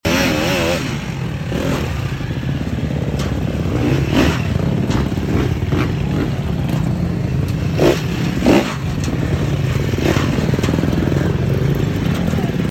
250cc MX 2 Class MXGP Sound Effects Free Download